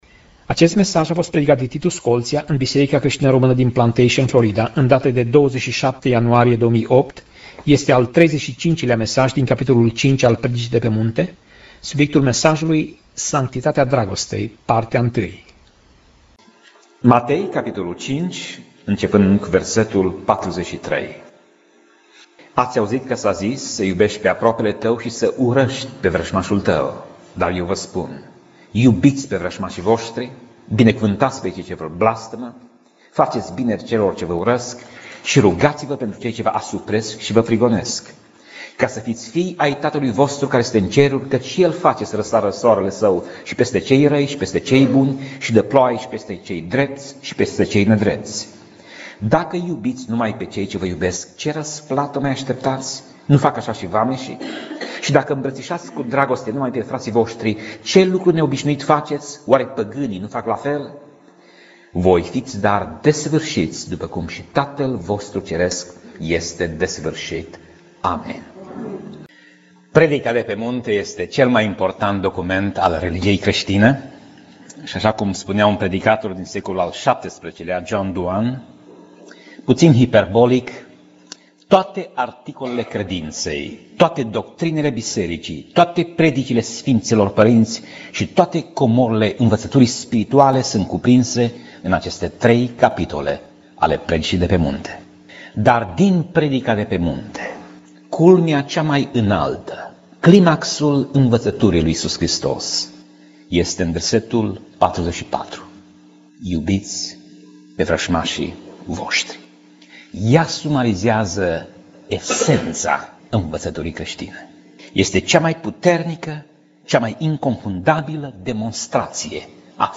Pasaj Biblie: Matei 5:44 - Matei 5:48 Tip Mesaj: Predica